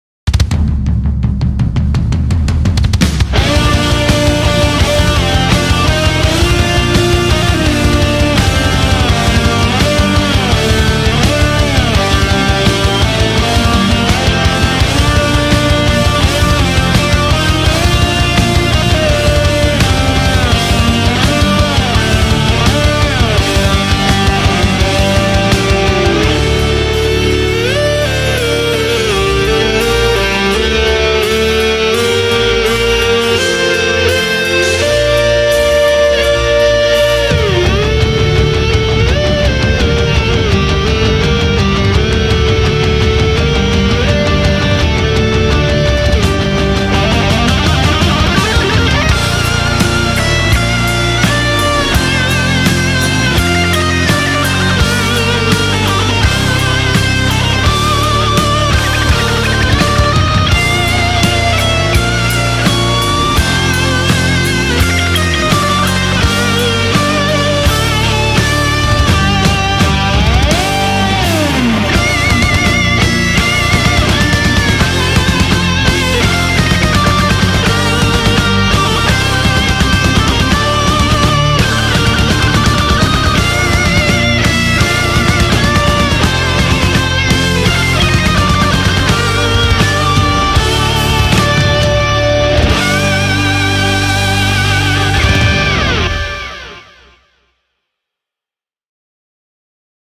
Gimn_Ukrainy_Rok_Versiya___CSHe_ne_umerla_Ukrainy_SongHouse_me.mp3